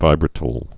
(vībrə-tl, -tīl)